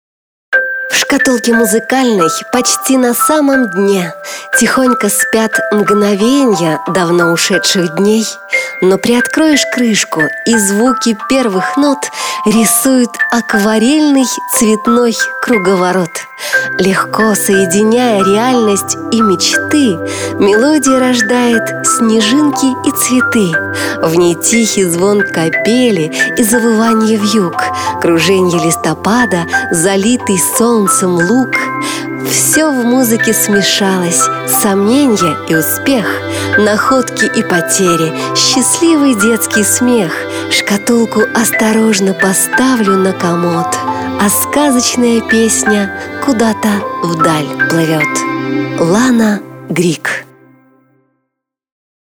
Сегодня, здесь и сейчас Вы можете абсолютно бесплатно скачать тематический аудиоролик ко Дню Музыкальных Шкатулок и транслировать его в своем торговом центре, супермаркете, магазине или кафе.